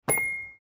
Chime.mp3